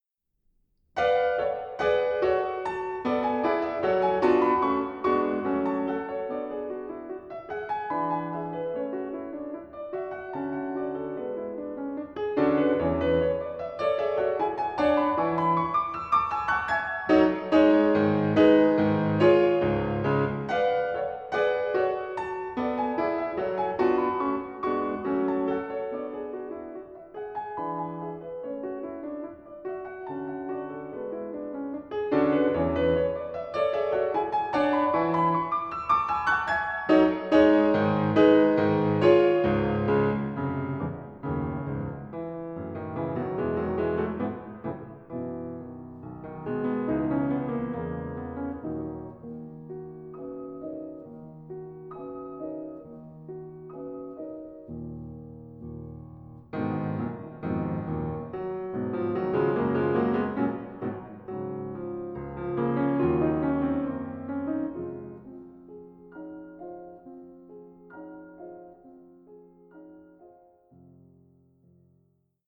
«Античный менуэт», исп. Анджела Хьюитт: